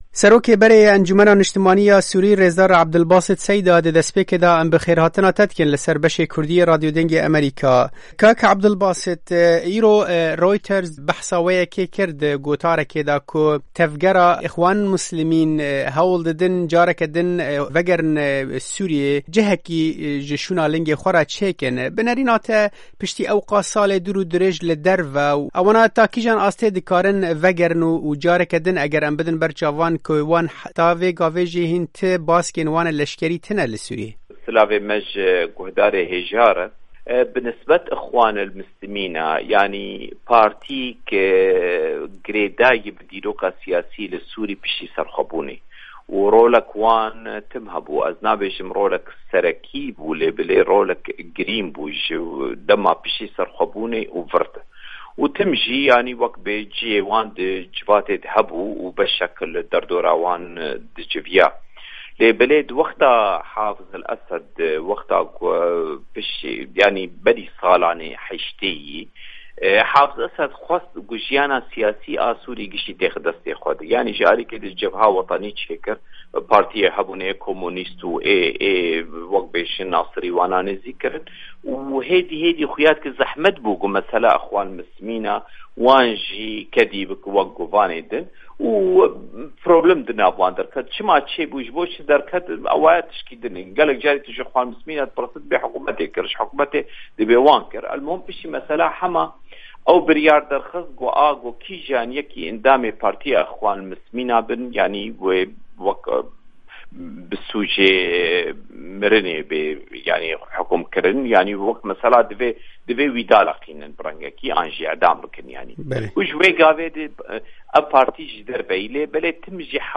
Serokê berê Encûmena Niștimanî ya Sûrî rêzdar Ebdulbasêt Seyda, ji Dengê Amerîka re ev babet șîrove kir û got: